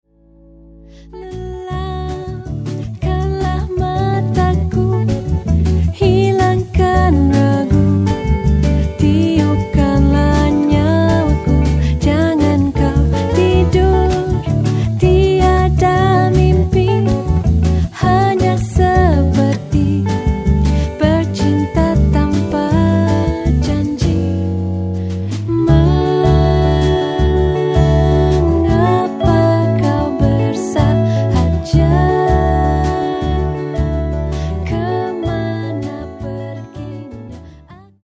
インディポップ/ワールド